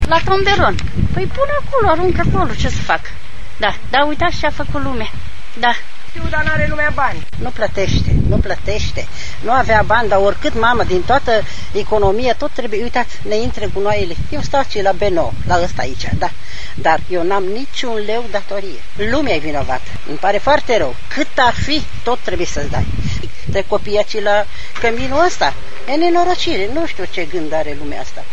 audio-cetateni.mp3